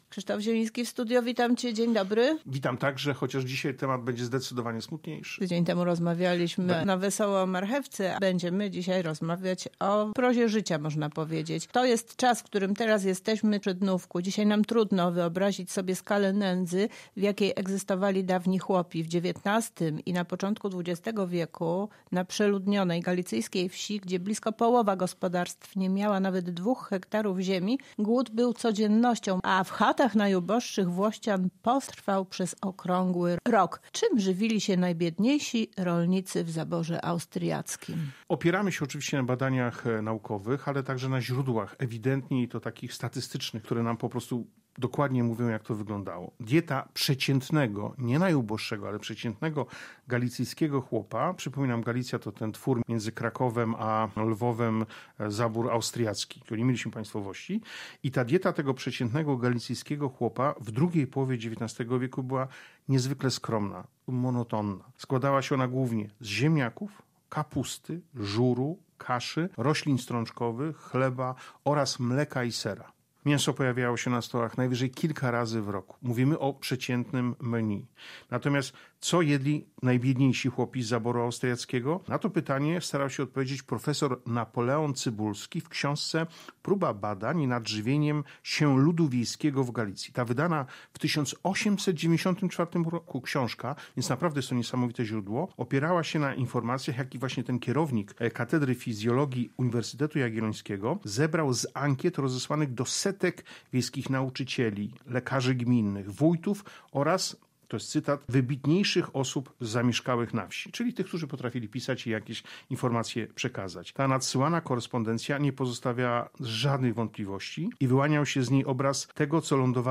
O tym rozmawiają w Kulinarnych Pogaduchach